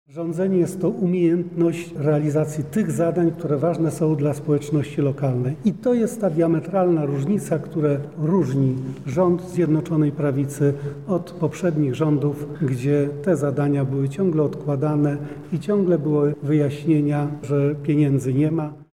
Bolesław Gzik-mówi wicewojewoda lubelski Bolesław Gzik